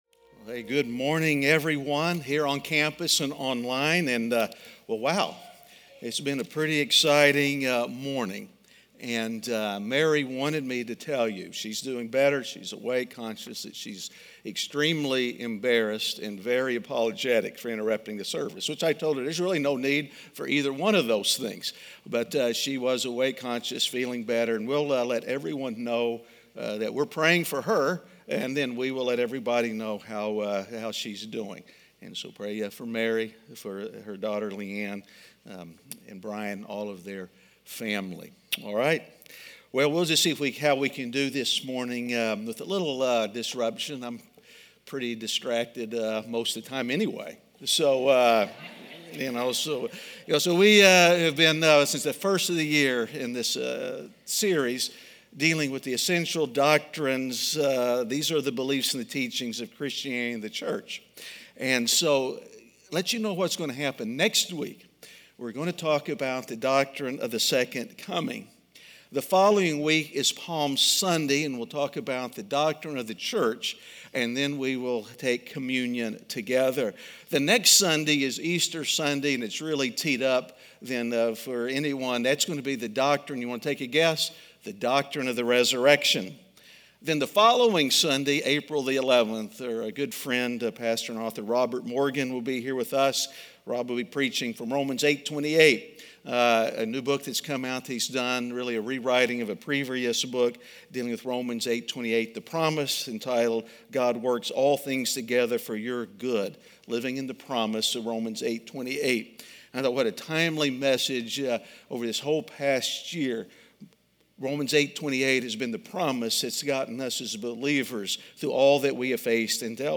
Is There A Doctrine In The House? (Week 11) - Sermon.mp3